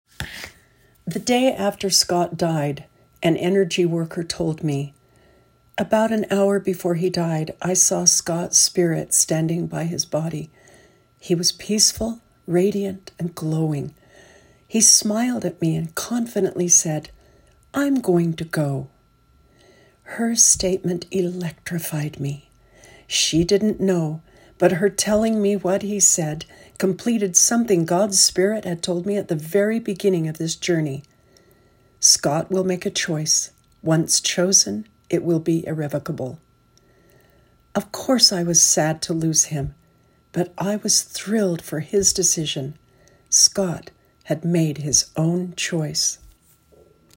Book Audio Sample